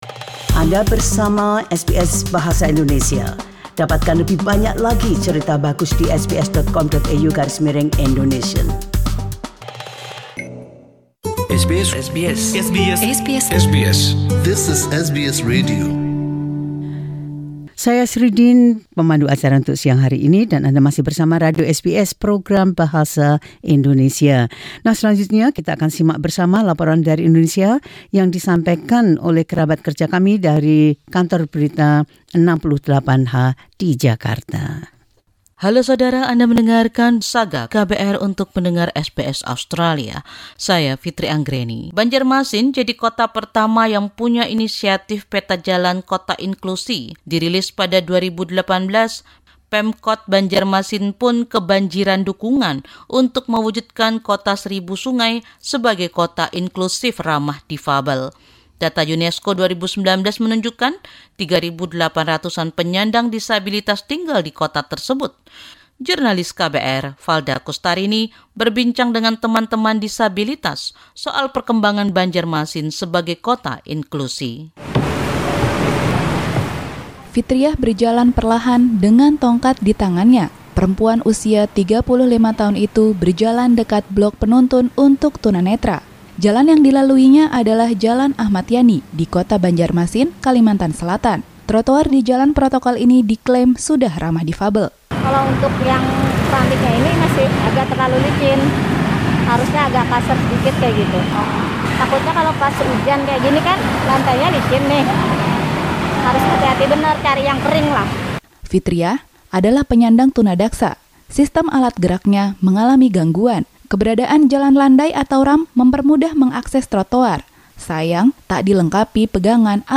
Tim KBR 68H turun ke jalan dan bertanya kepada warga difabel, bagaimana Banjarmasin ramah difabel sekarang.